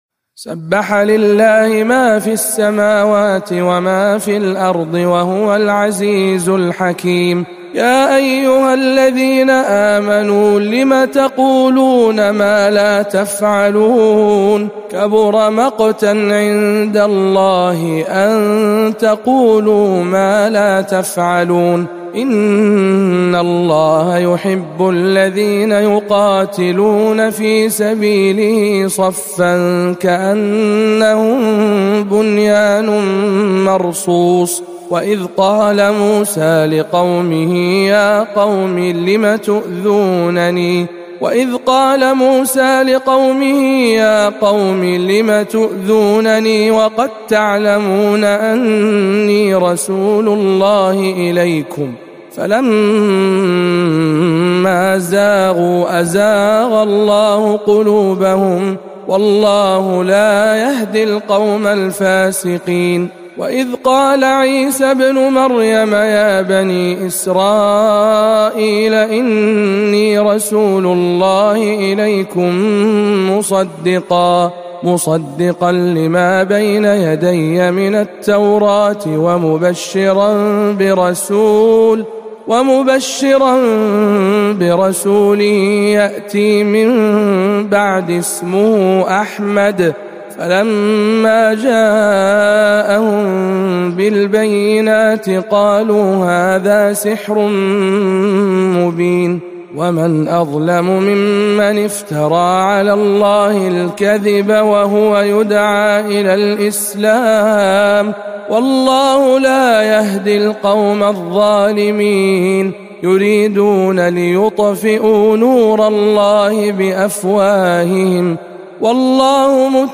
سورة الصف بجامع معاذ بن جبل بمكة المكرمة - رمضان 1439 هـ